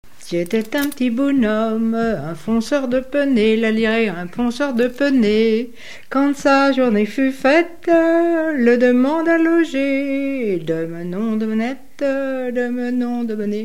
Genre laisse
Chansons et commentaires
Pièce musicale inédite